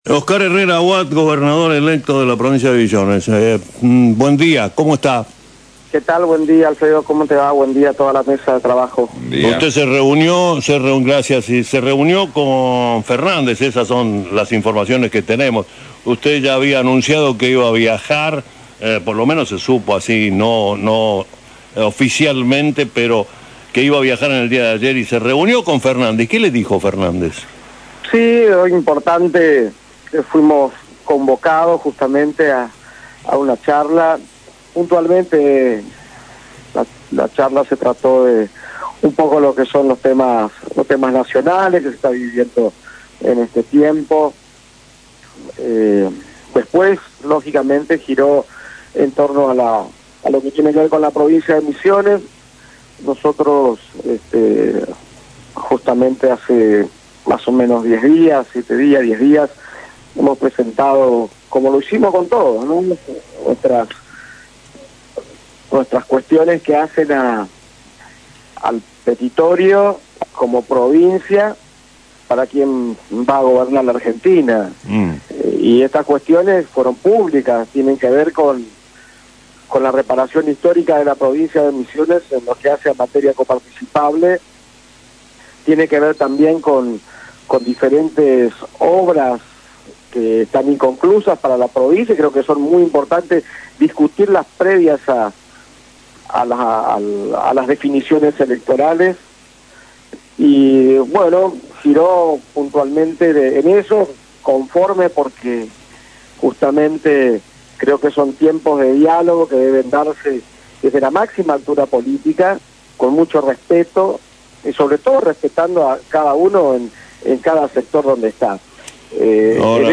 Artículo publicado por sitio FM Show